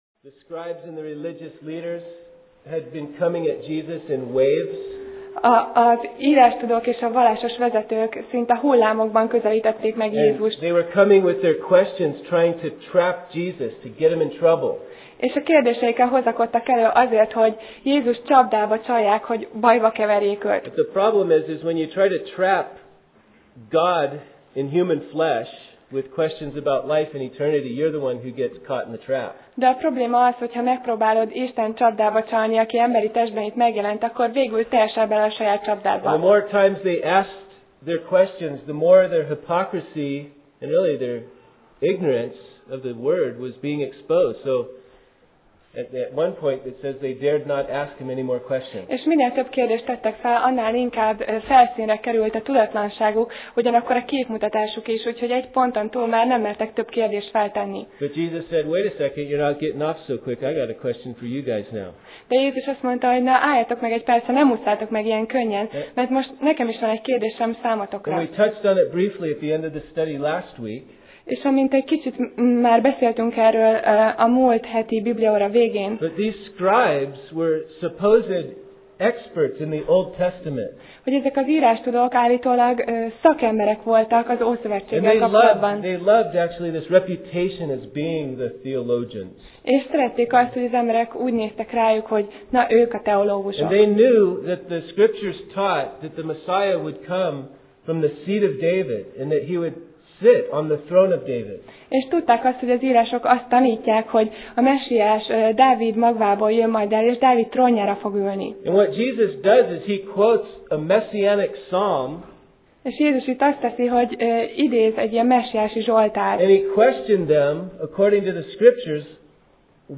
Márk Passage: Márk (Mark) 12:35-40 Alkalom: Vasárnap Reggel